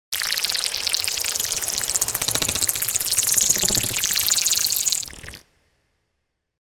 ゾンビ・スプラッター系_7（虫っぽい気持ち悪い音_2）